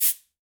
Cabasa.wav